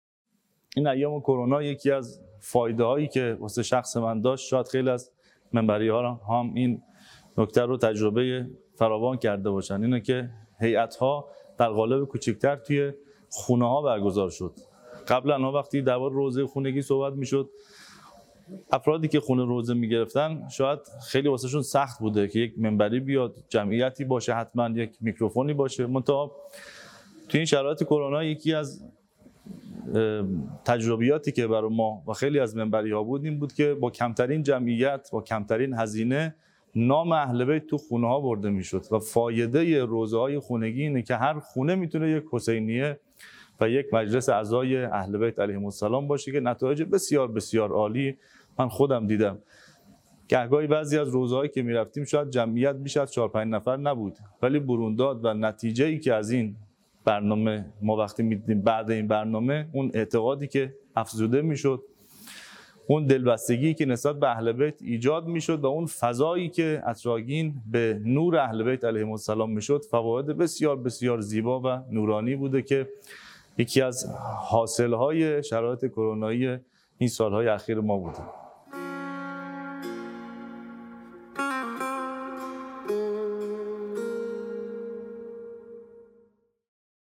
گفتگویی
در اولین نشست صمیمانه جمعی از منبری‌های جوان هیأت‌های کشور